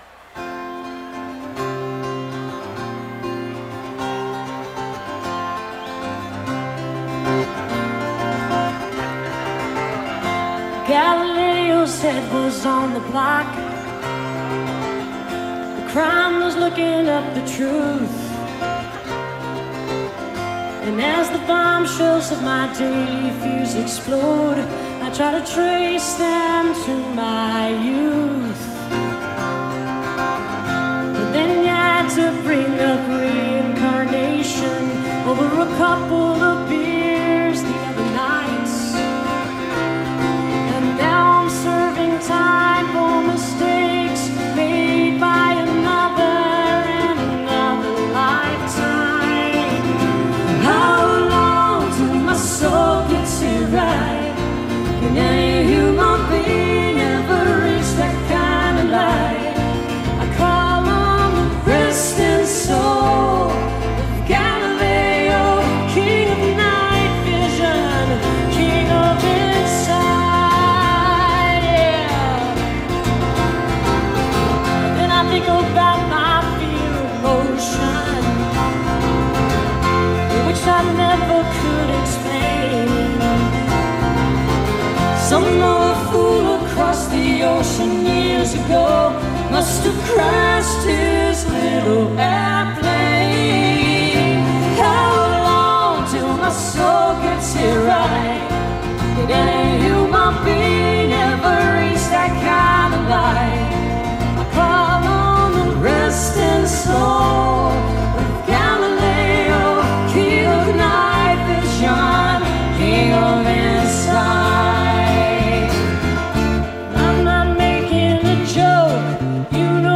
1993-08-22: autzen stadium - eugene, oregon